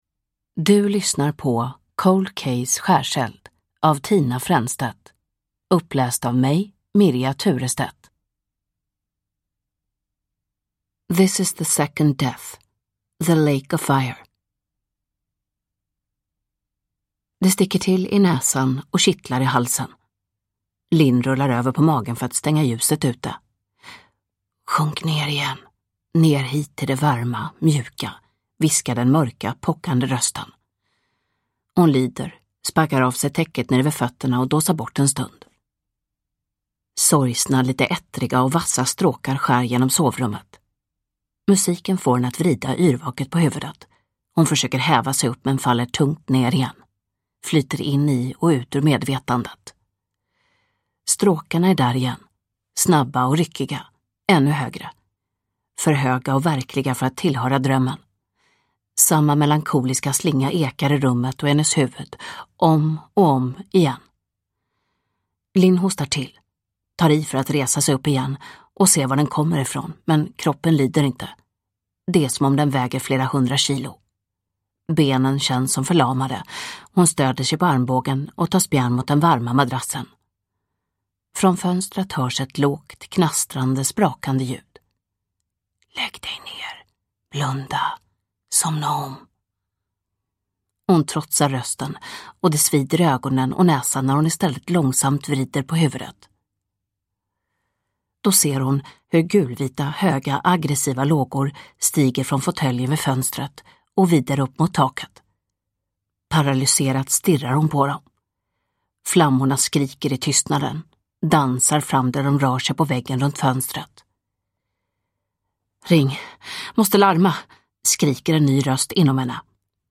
Uppläsare: Mirja Turestedt
Ljudbok